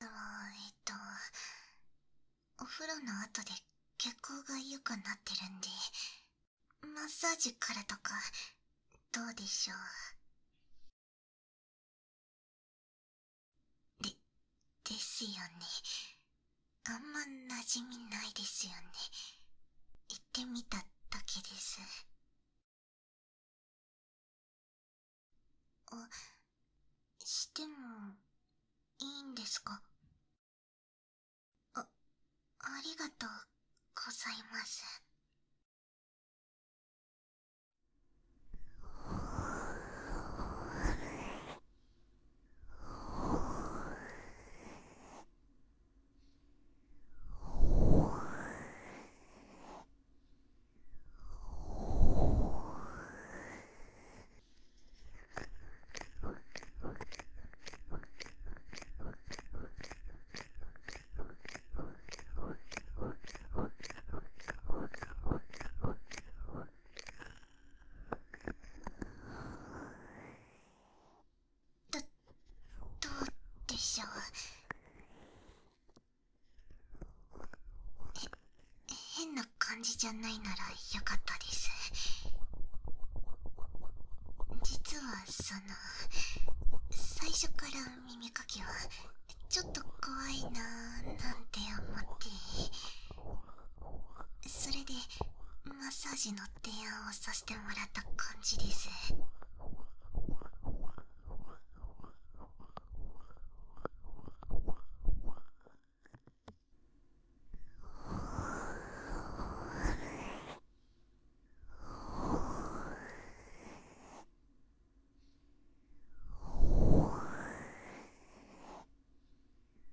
掏耳 环绕音 ASMR
Tr04_ループ用音源_おまけ.m4a